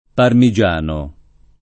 parmiJ#no] etn. (di Parma) — sim. i cogn. Parmigiani, -no e, da varianti ant. o dial., Parmeggiani, -no, Parmegian [parmeJ#n], Parmegiani, -no, Parmesan [parme@#n], Parmesani, Parmiciano, Parmiggiani, -no — cfr. parmense